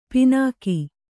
♪ pināki